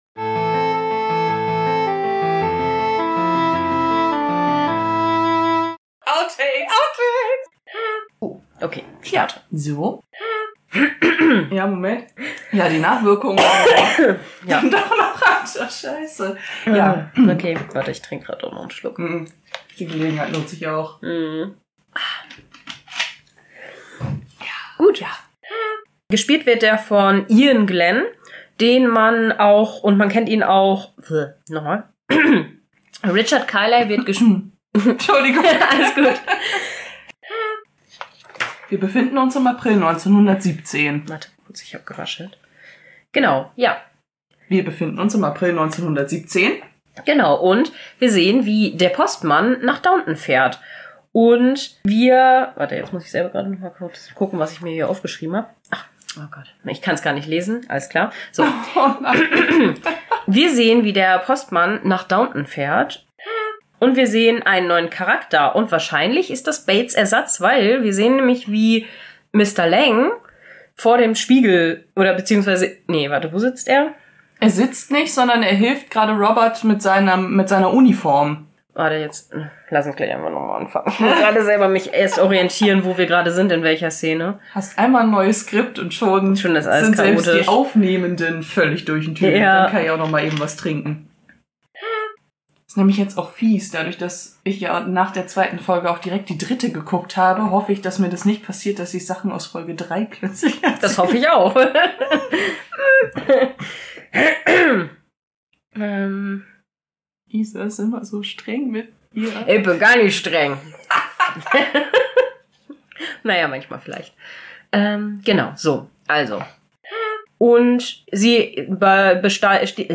Oh man es war echt zu warm XD dieses mal mit ganz schön vielen Versprechern und Katzenaction.